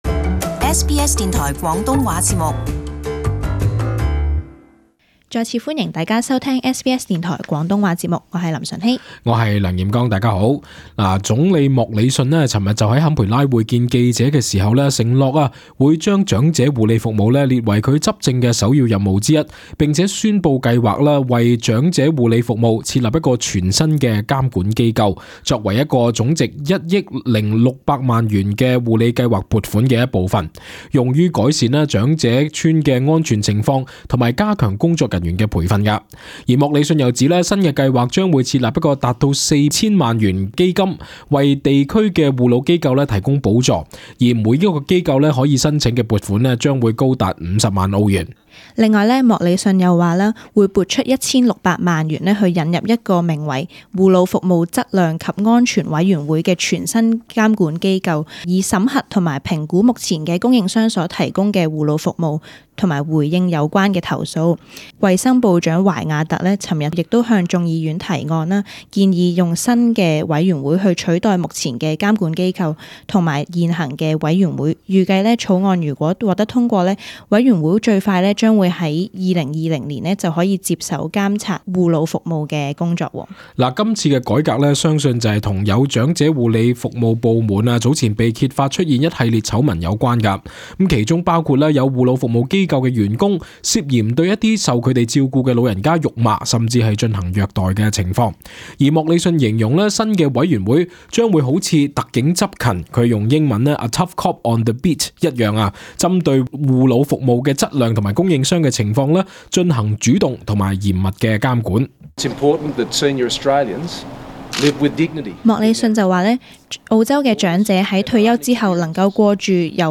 【時事報導】政府承諾撥過億改善長者服務